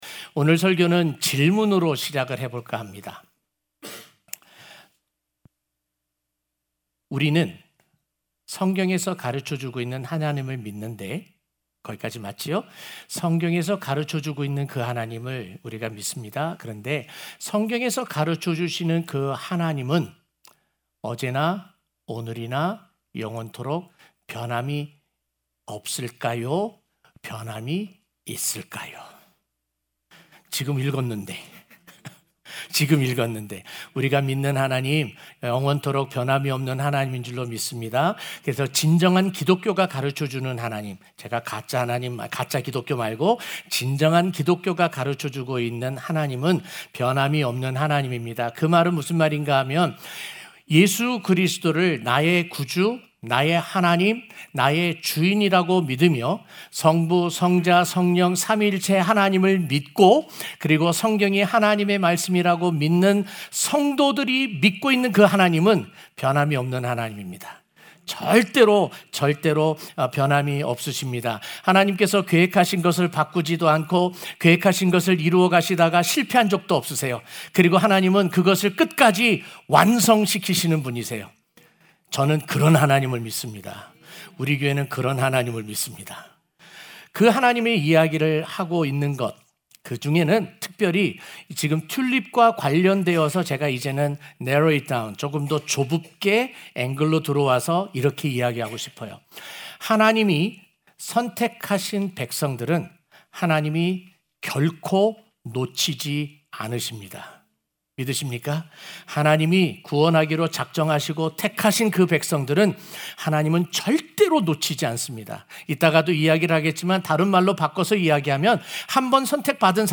05-A-Perseverance-of-the-saints-1-Sunday-Morning.mp3